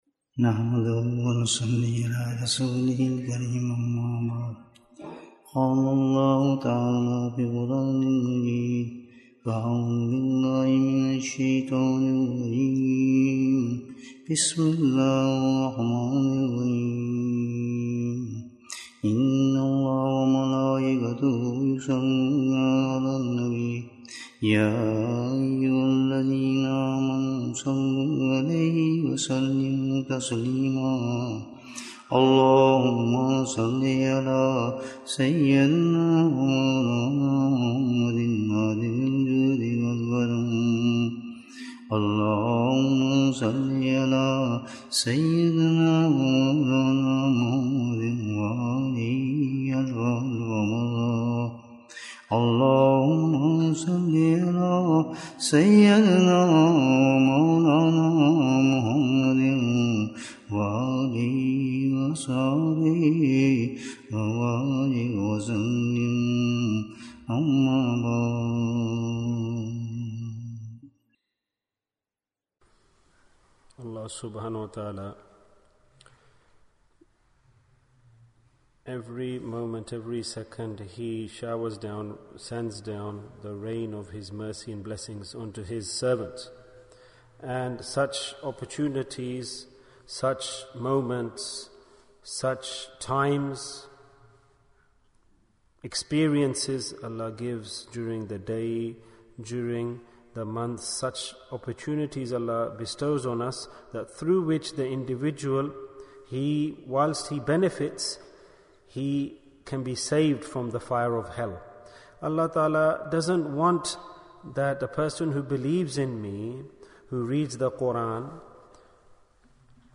Why do People Worship More in Ramadhan? Bayan, 29 minutes23rd March, 2023